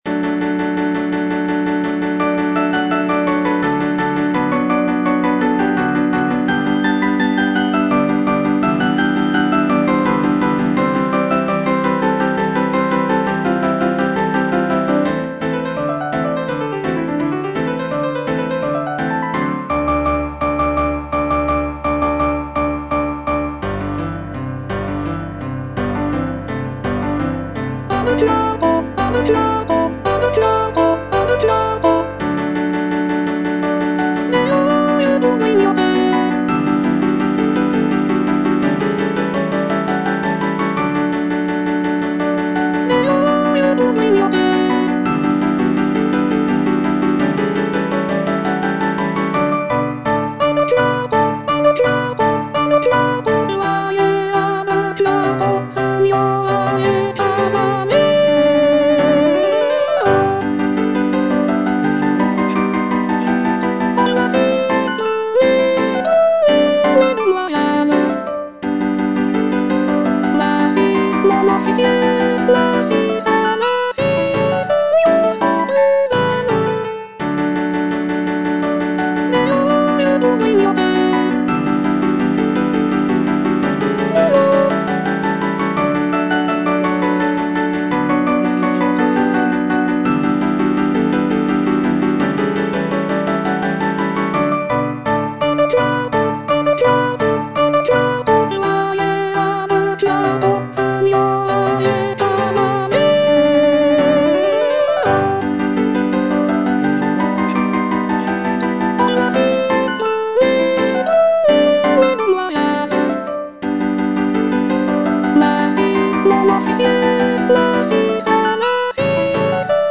G. BIZET - CHOIRS FROM "CARMEN" A deux cuartos (soprano II) (Voice with metronome) Ads stop: auto-stop